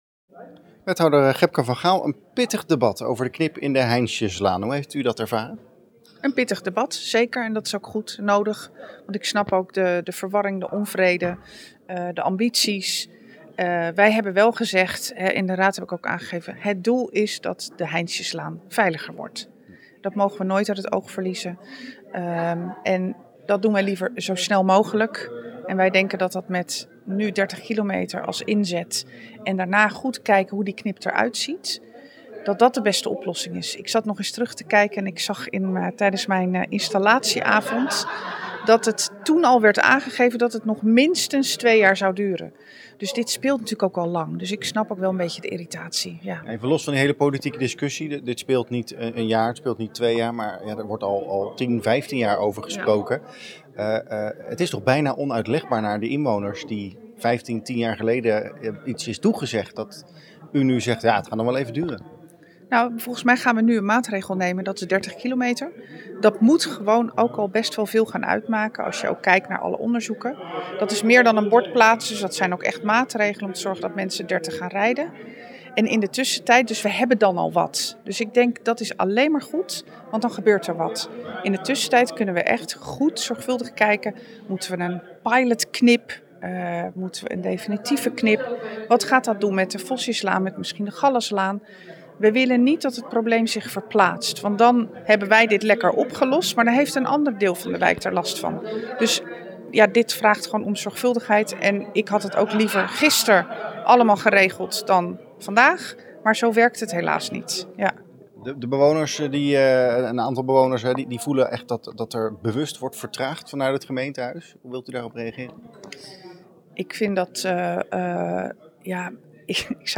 AUDIO: Wethouder Gebke van Gaal over de knip.